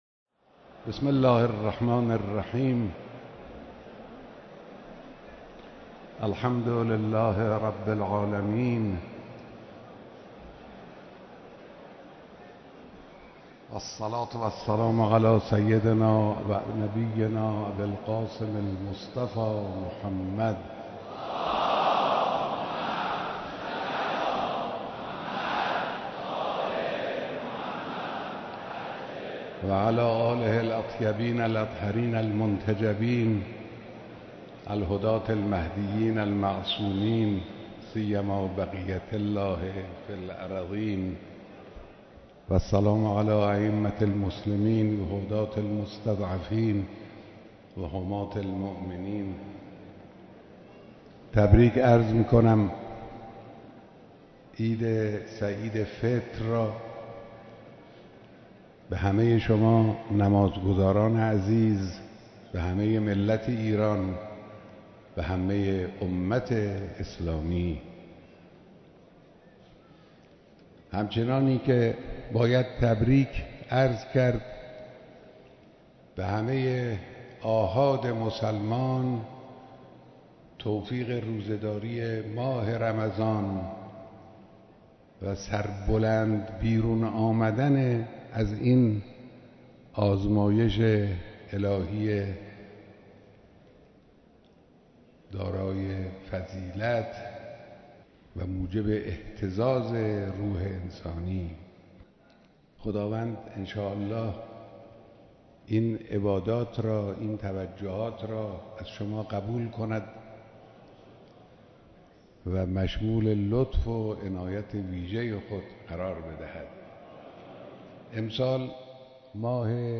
خطبه‌های نماز عید فطر در مصلای امام خمینی (رحمه‌الله)